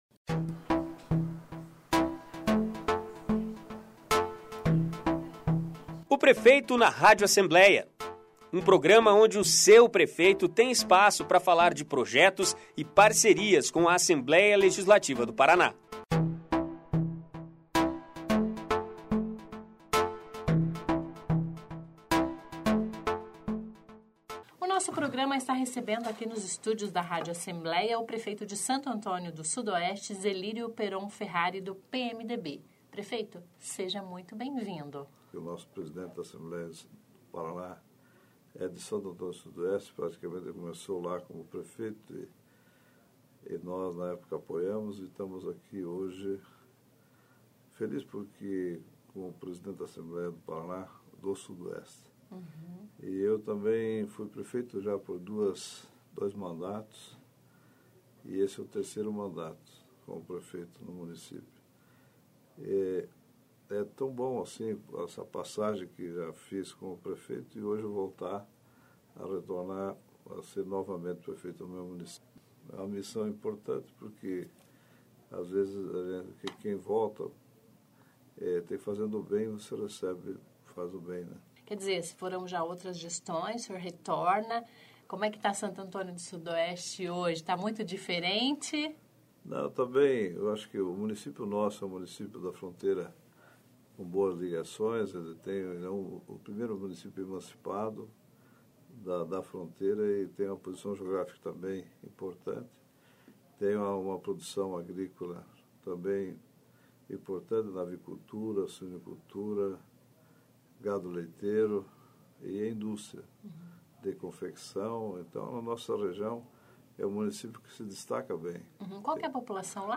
Ouça a íntegra da entrevista com Zelírio Peron Ferrari (PMDB), prefeito de  Santo Antônio Do Sudoeste, o convidado desta semana do programa "Prefeito na Rádio Alep".